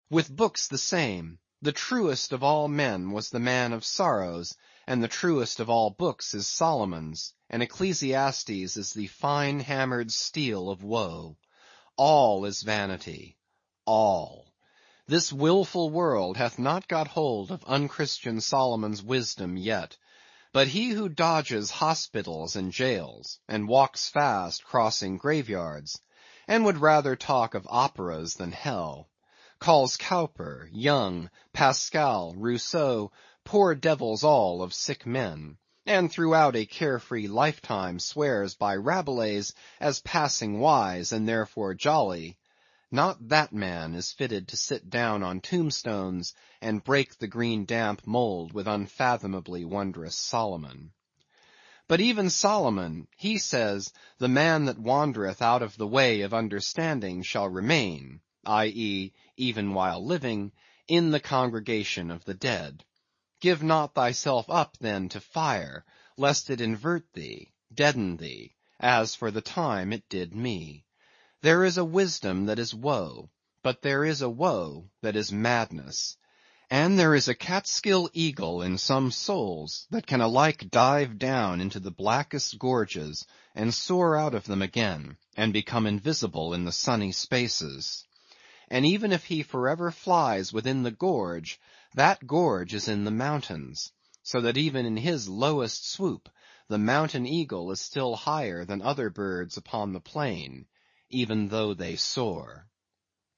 英语听书《白鲸记》第821期 听力文件下载—在线英语听力室